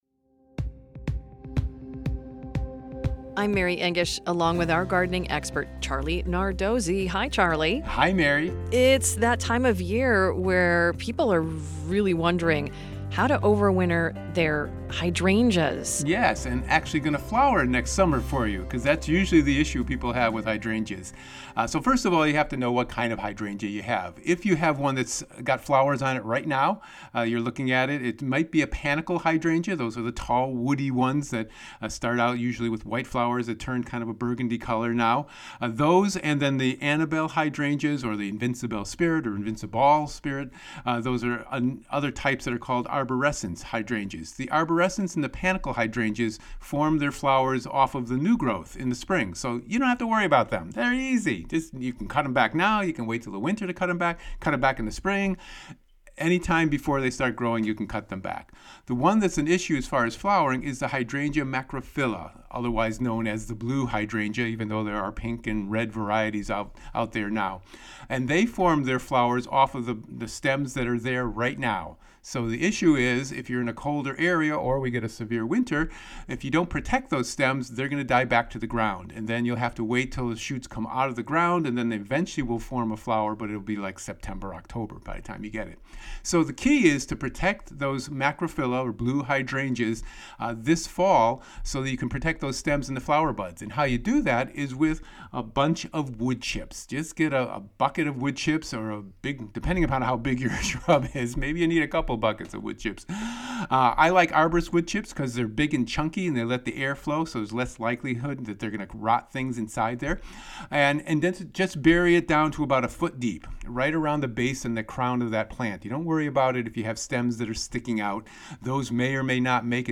for a conversation about gardening, and to answer your questions about what you're seeing in the natural world.